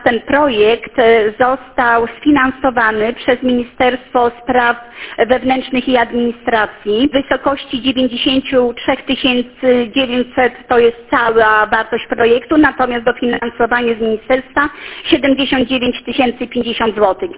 – Przejścia będą ostrzegały kierowców, gdy do drogi zbliży się pieszy – mówi starosta powiatu gołdapskiego, Marzanna Wardziejewska.